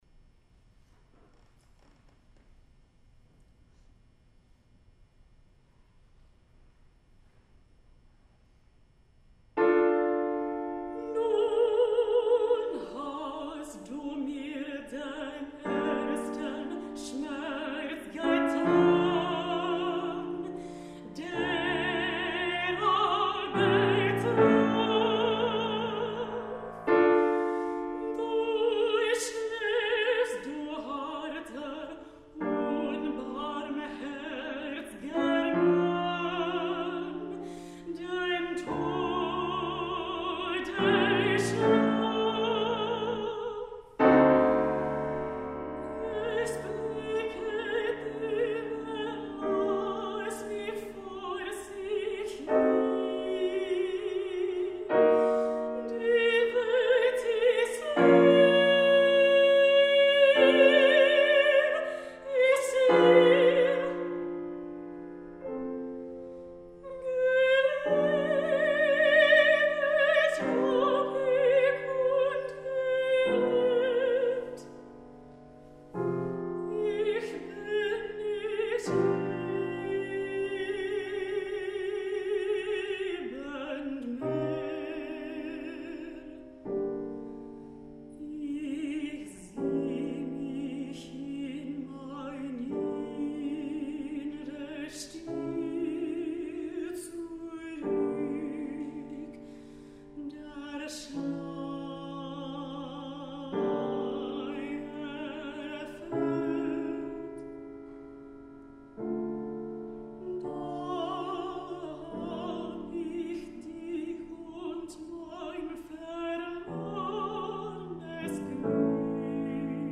Mezzo-Soprano
Senior Recital